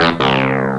SFX
Final Round - Incorrect.ogg